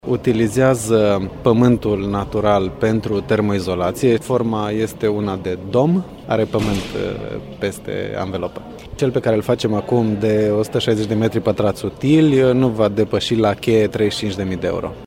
Vox-Ro-Energy.mp3